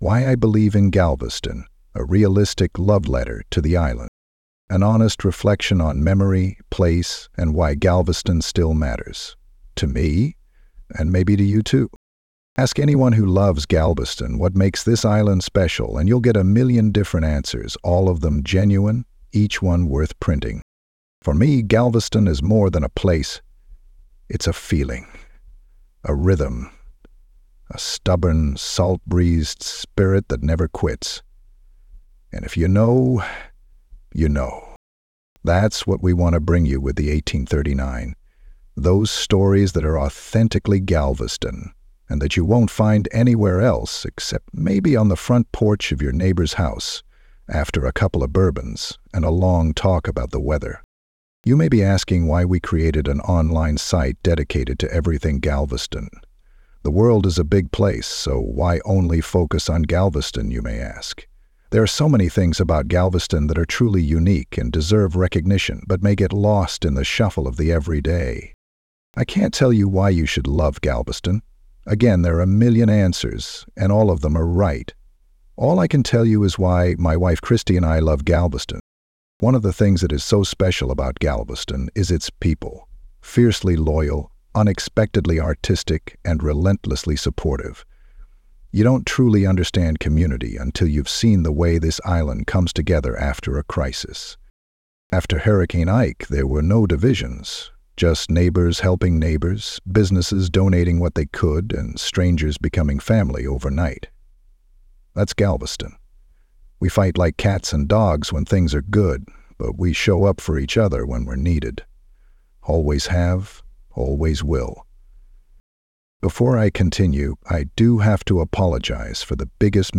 Here’s an audio version of the story, read in my own words.